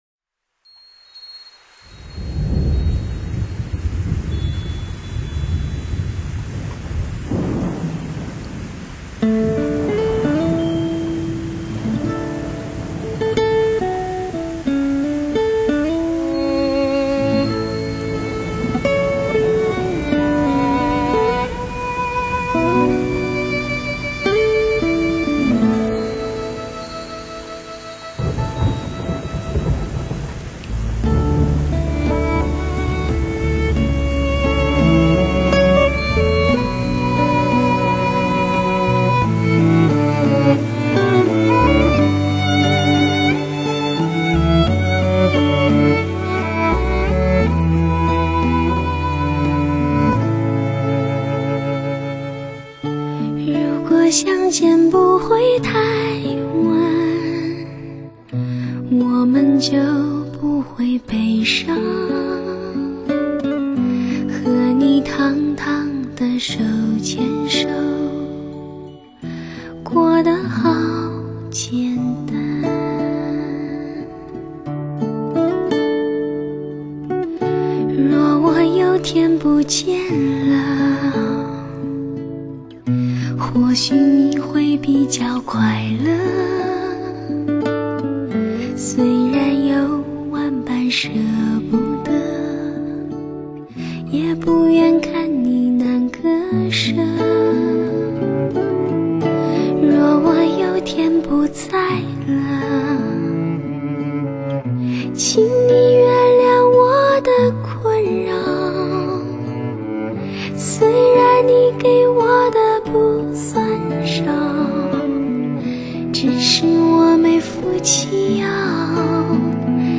这是一张全新音效测试大碟，人声演绎自然极至，不加任何人为修饰！